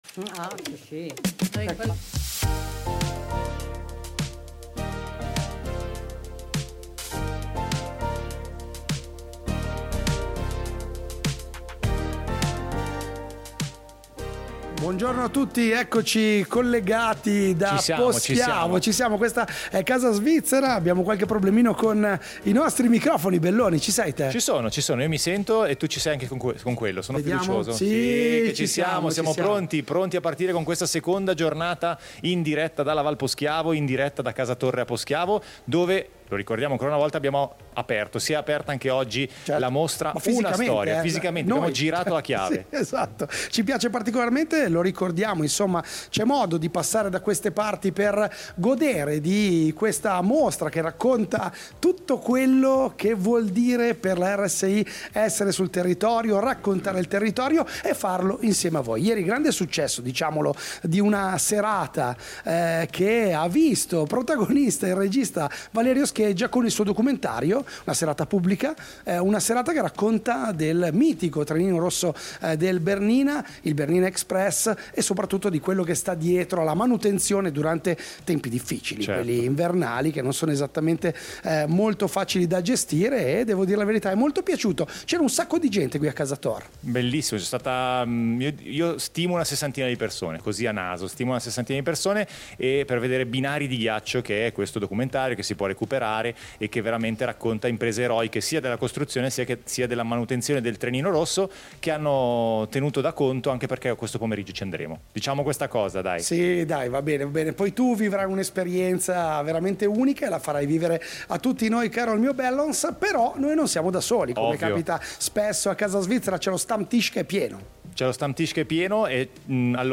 Val Poschiavo in diretta